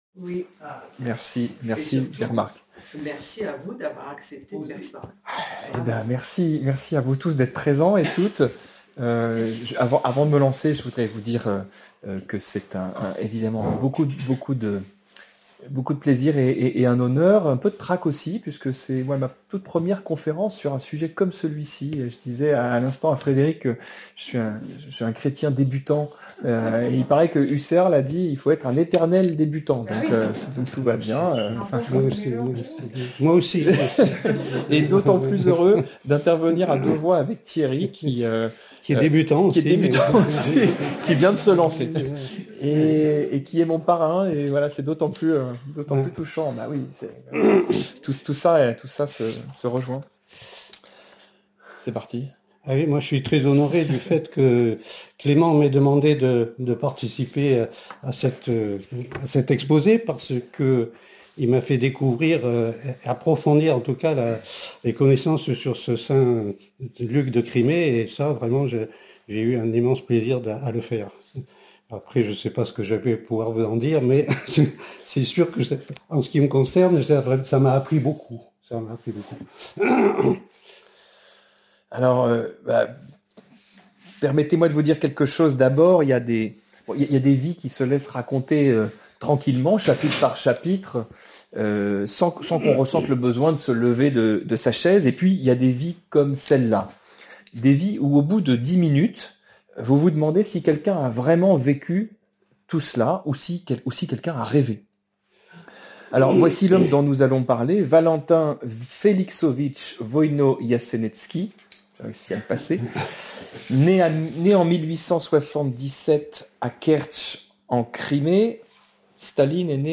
Enregistrement audio de la conférence sur Saint Luc de Crimée
Enregistrement-conference-Saint-Luc-de-Crimee.mp3